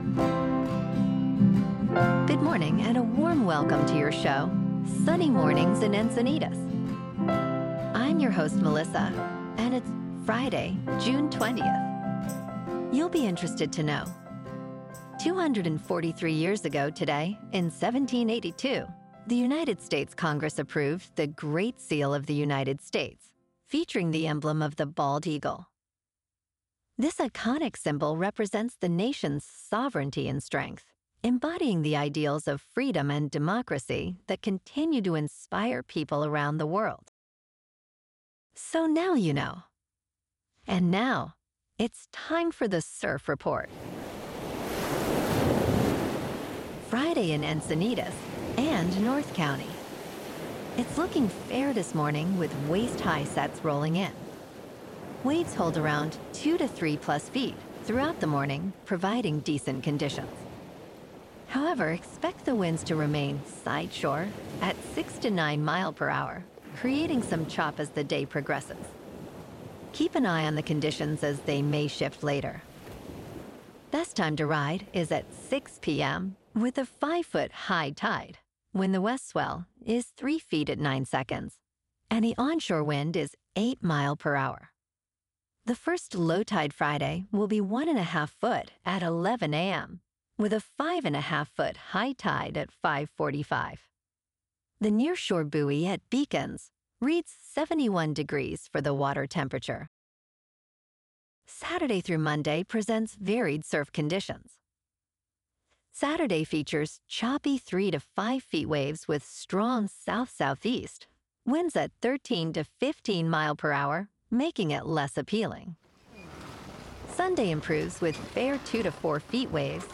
The #1 Trusted Source for AI Generated News™